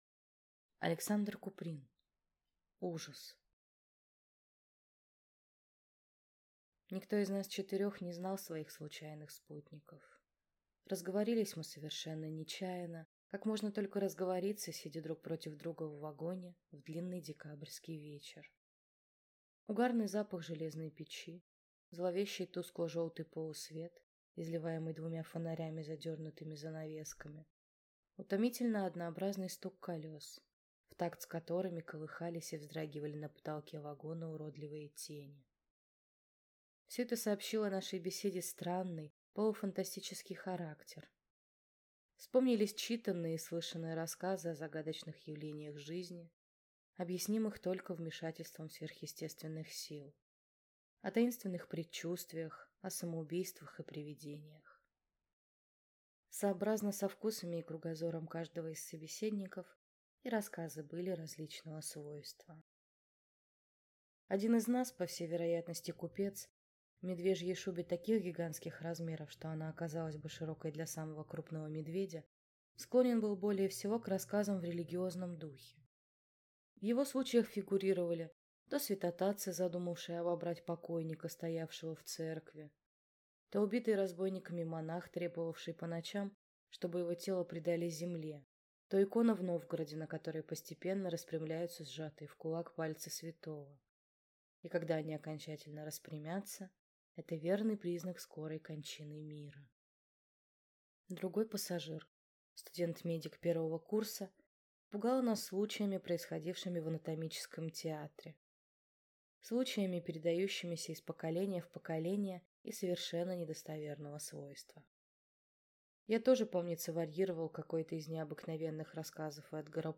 Аудиокнига Ужас | Библиотека аудиокниг